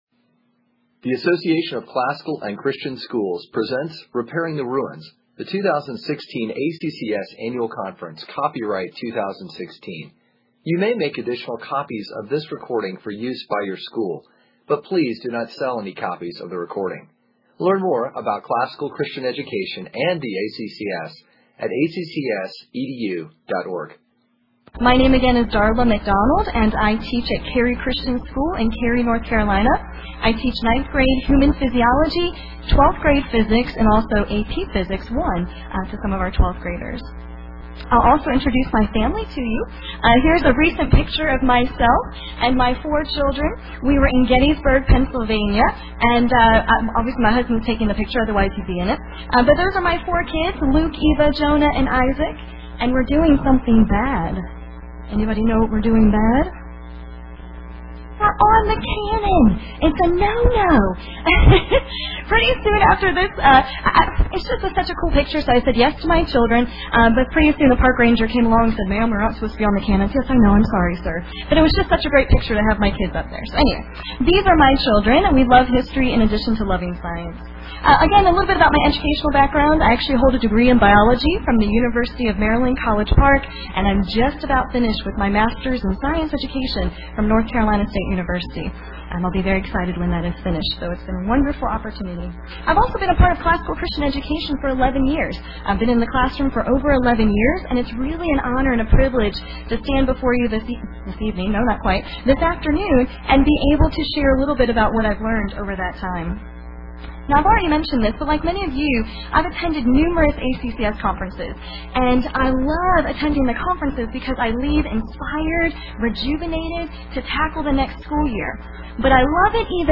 2016 Workshop Talk | 1:03:44 | All Grade Levels, Science